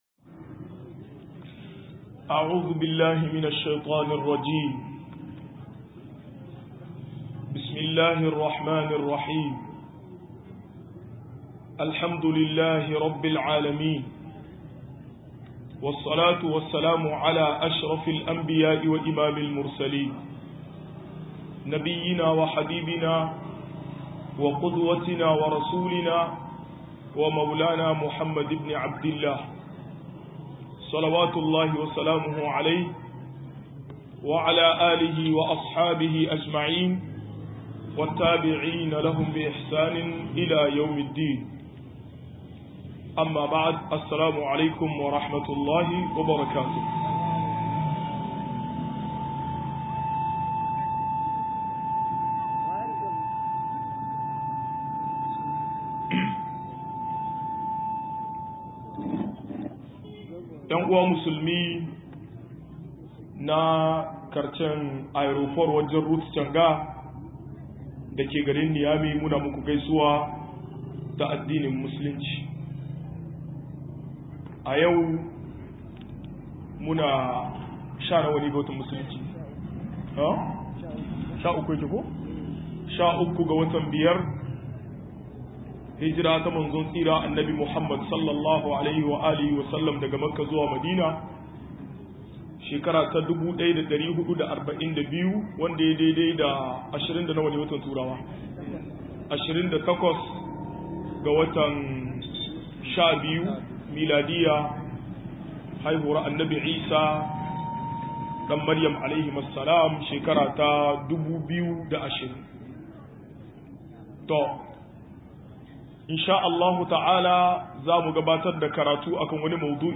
100-Hukumcin Idi a Musulunci - MUHADARA